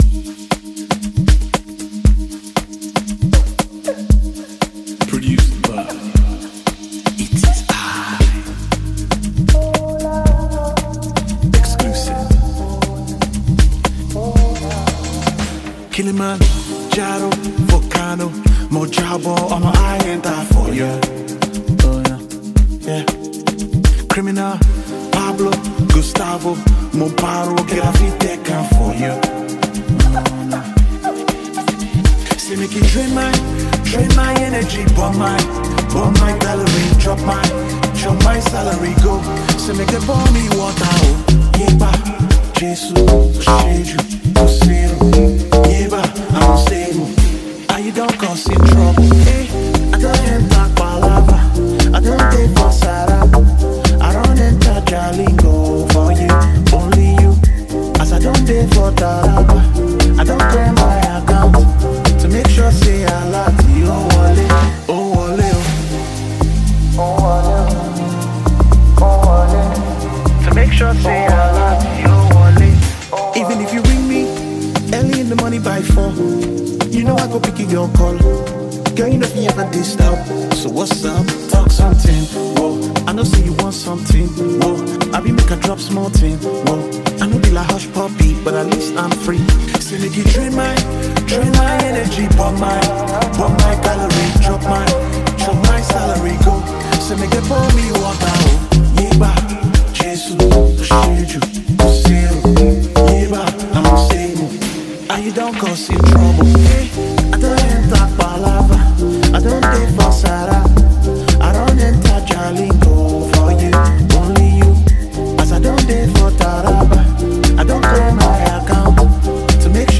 powerful jam